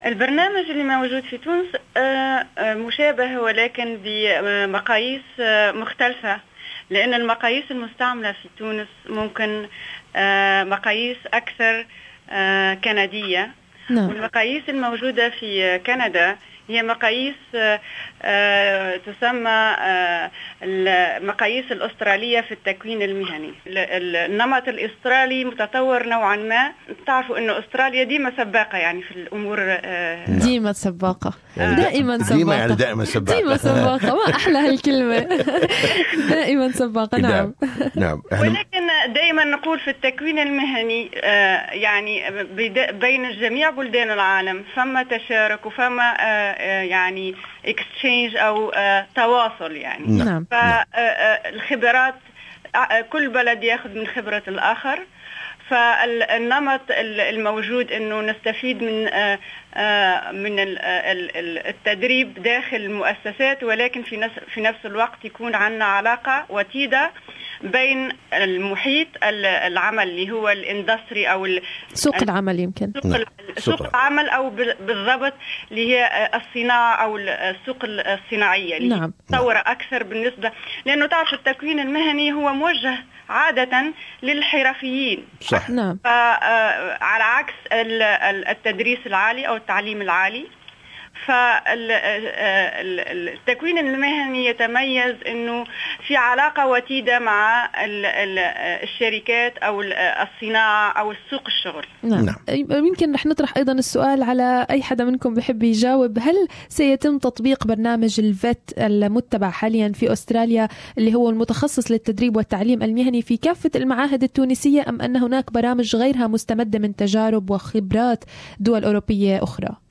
The Tunisian delegation interviewed by SBS Arabic 24 and they spoke about comparison of vocational education and training pro gramme in Tunisia and Australia.